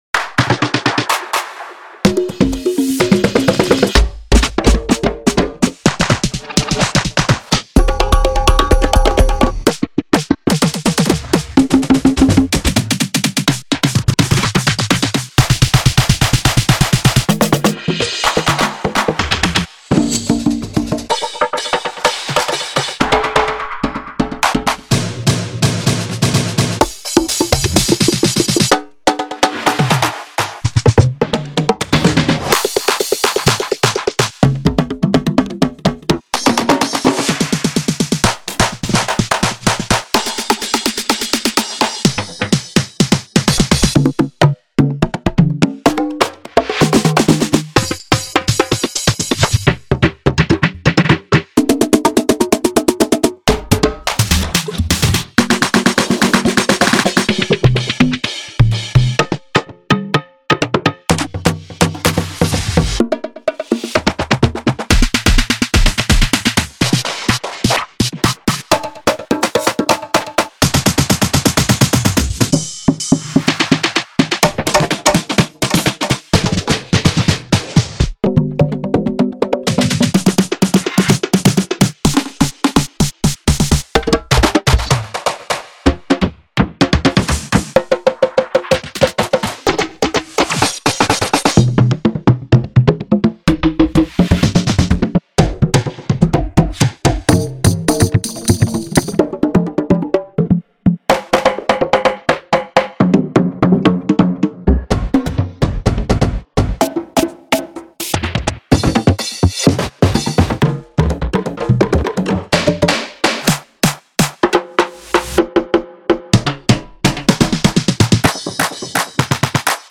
Genre:House
注意：オーディオデモは大きく、圧縮され均一に聞こえるように処理されています。
100 drum fills